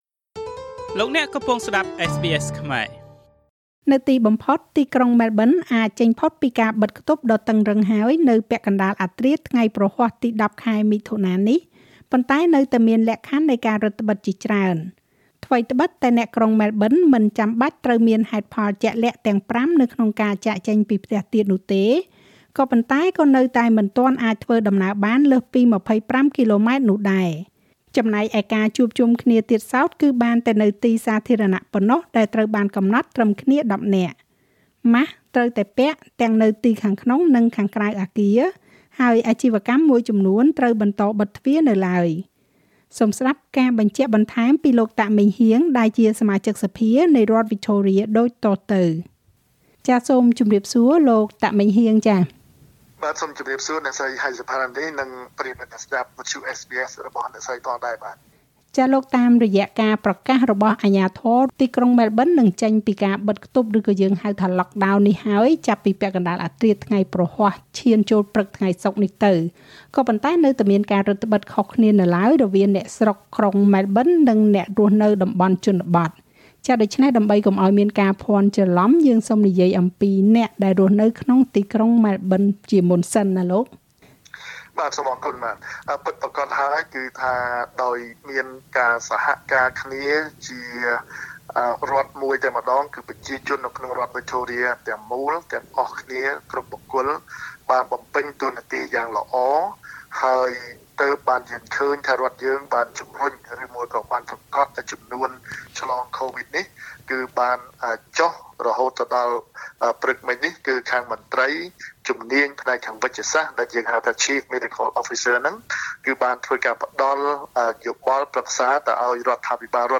សូមស្តាប់ការបញ្ជាក់បន្ថែមពីលោក តាក ម៉េងហ៊ាង ដែលជាសមាជិកសភានៃរដ្ឋវិចថូរៀដូចតទៅ។
លោក តាក ម៉េងហ៊ាង សមាជិកសភានៃរដ្ឋវិចថូរៀ។ Source: MP Heang Tak